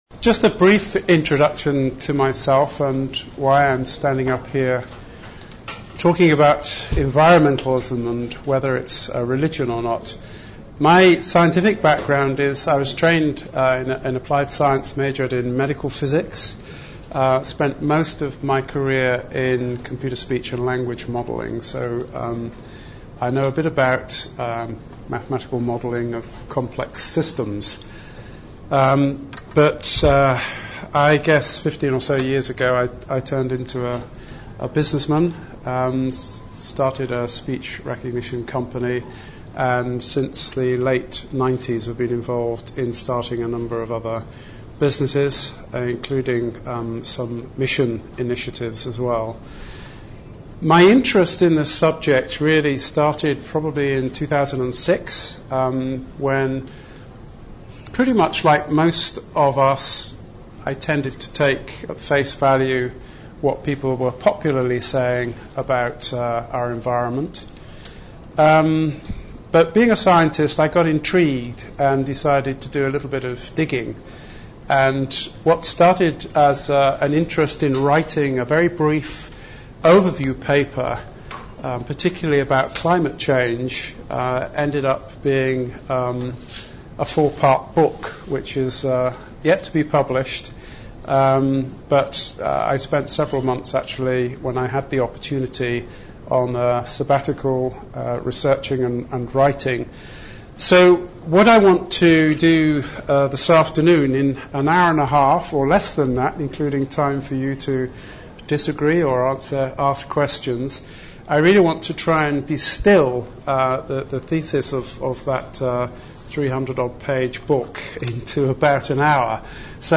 Event: ELF Workshop Topic